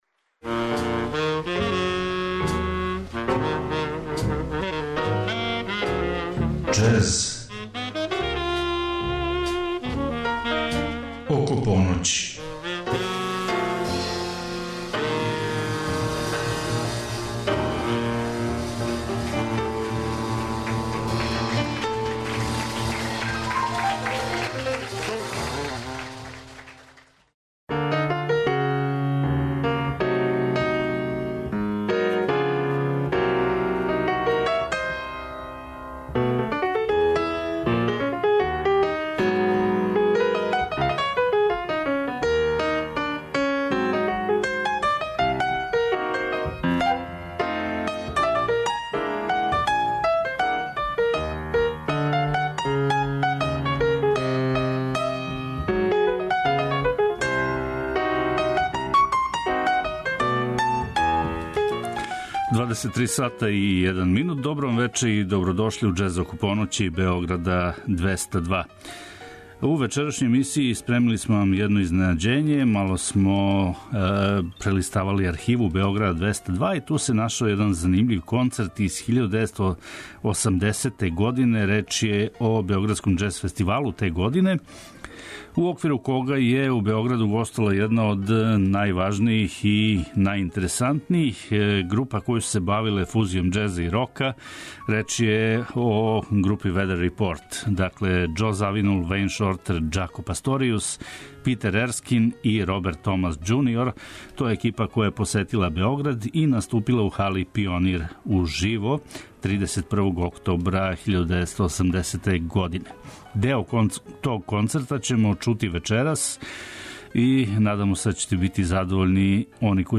Емисија је магазинског типа и покрива све правце џез музике, од Њу Орлиенса, преко мејнстрима, до авангардних истраживања. Теме су разноврсне - нова издања, легендарни извођачи, снимци са концерата и џез клубова, архивски снимци...